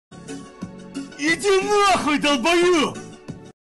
idi naxyu melstroi Meme Sound Effect